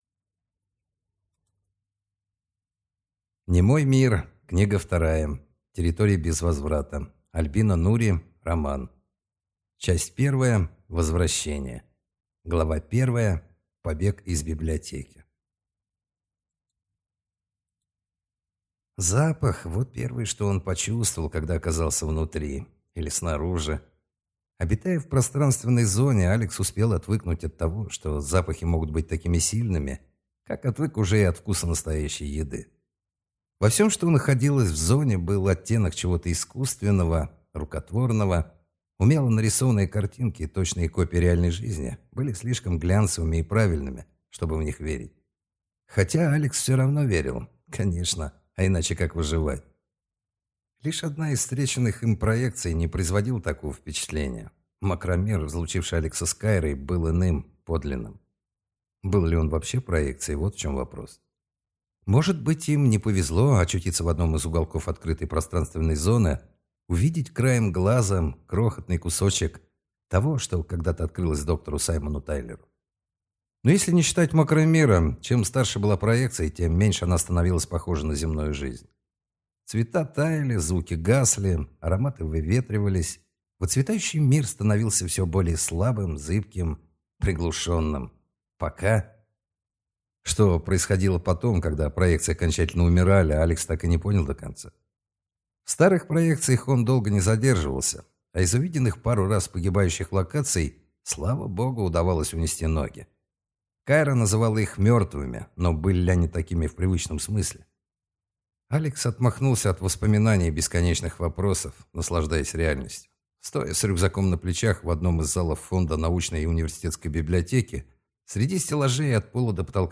Аудиокнига Территория без возврата | Библиотека аудиокниг